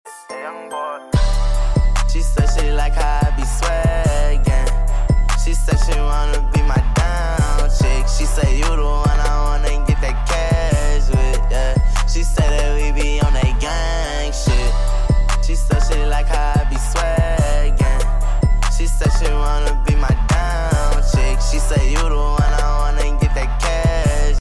cleanest exhaust setup out. go sound effects free download